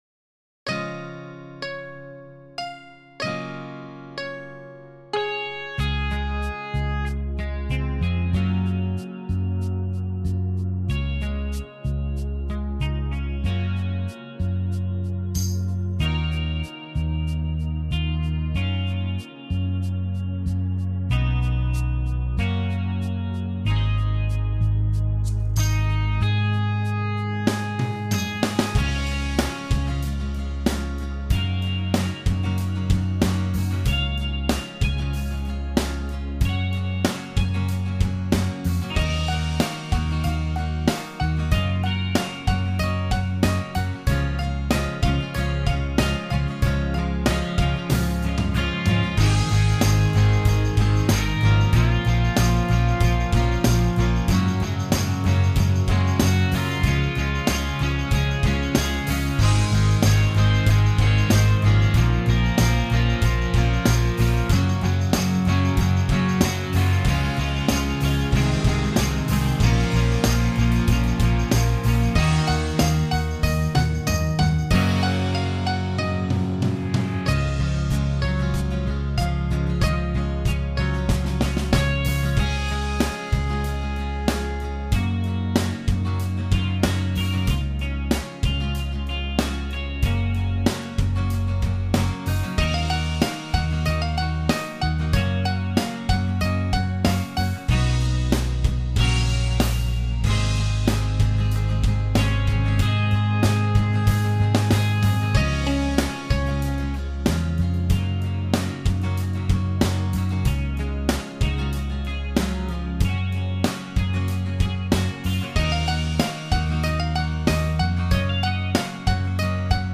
Karaoke i els vídeos originals.